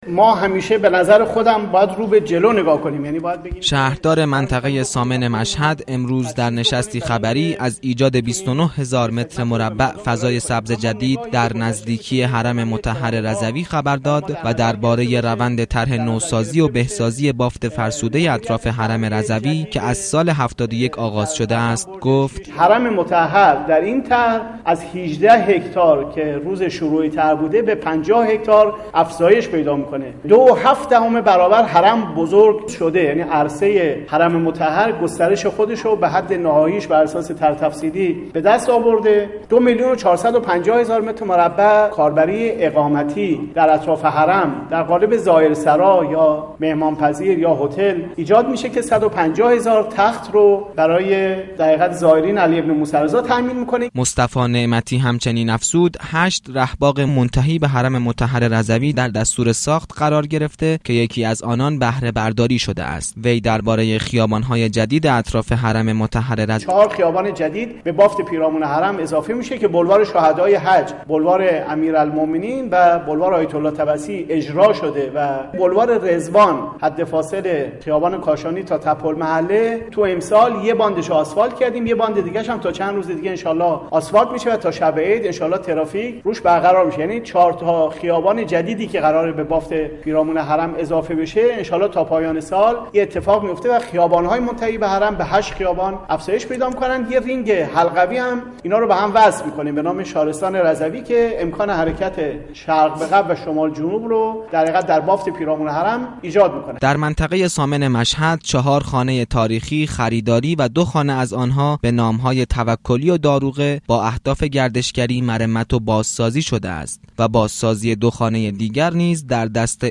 مصطفی نعمتی شهردار منطقه ثامن مشهدمقدس امروز در نشستی خبری گفت : در بافت اطراف حرم مطهر امام رضا (ع) چهار خیابان اصلی منتهی به حرم وجود داشت كه در طرح جدید به هشت خیابان تبدیل می‌شود